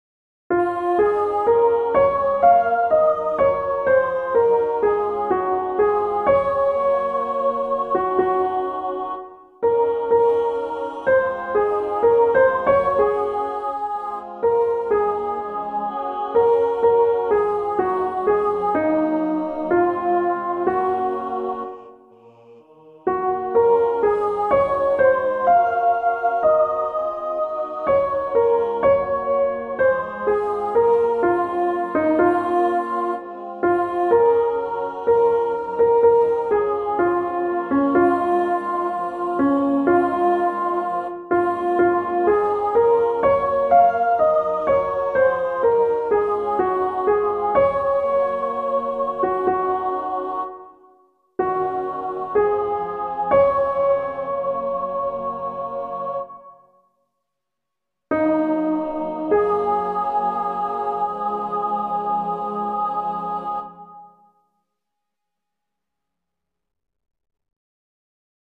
Welcome to the schedule and download site for practice files, for the use of the Chamber Choir at First Universalist Church of Denver (Colorado).
Soprano 1
RiseUpMyLoveMyFairOne-sop1.mp3